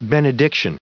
added pronounciation and merriam webster audio
524_benediction.ogg